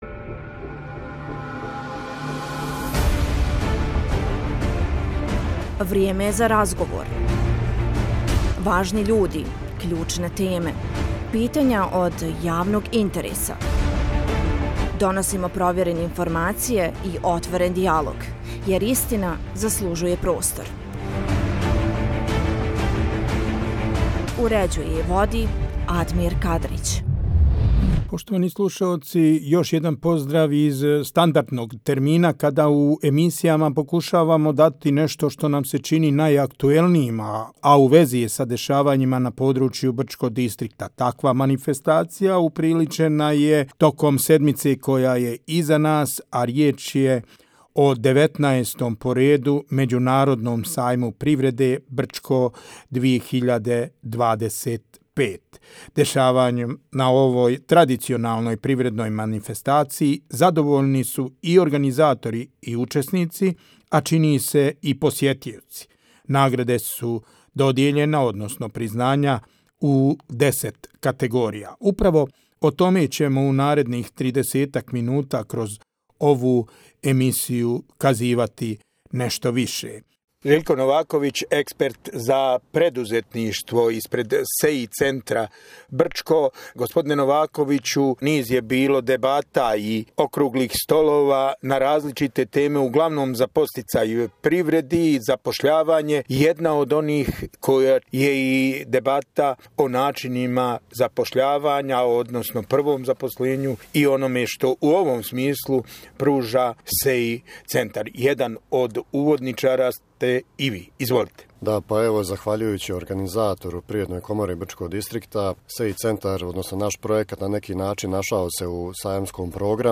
U emisiji “Vrijeme je razgovor” smo razgovarali akterima Međunarodnog sajma privrede u Brčkom među kojima su i nagrađeni učesnici sajma.